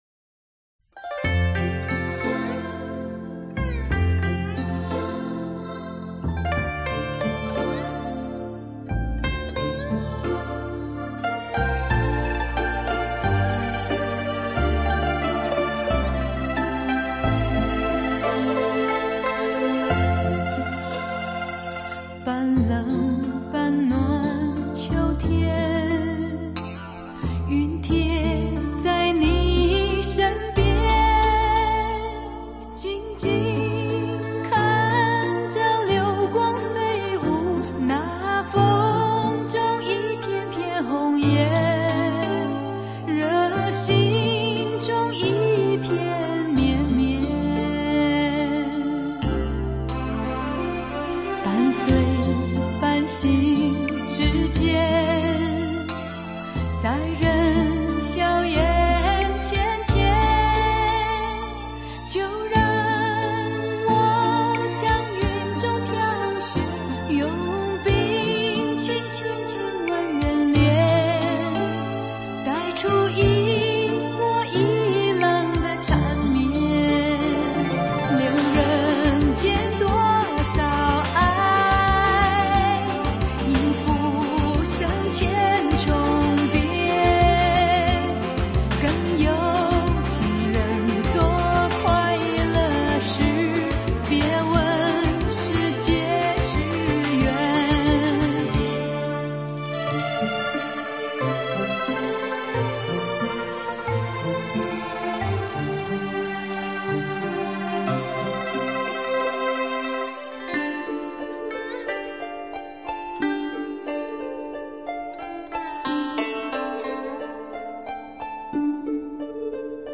主题曲
一段古筝开场，清亮明透的音色中不失温婉，立刻抓住了听众的耳。